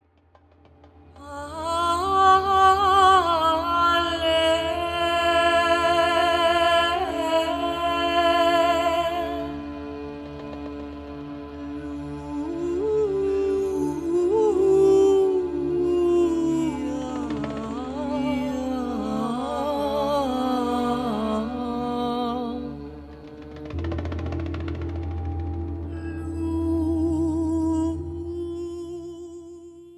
Armenian hymn